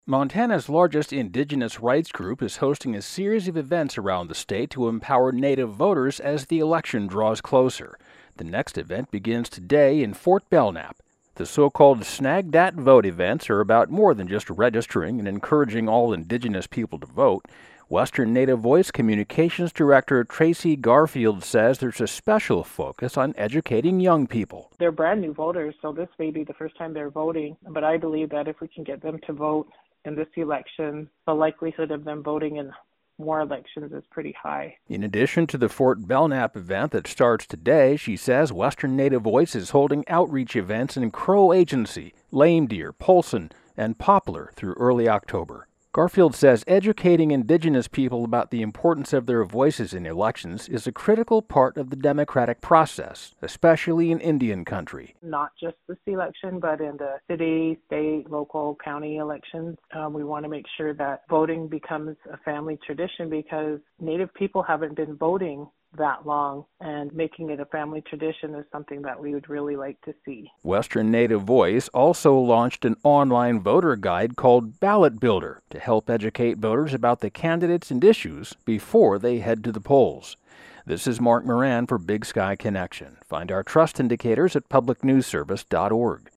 (Additional pronouncer: Belknap, "BELL-nap.")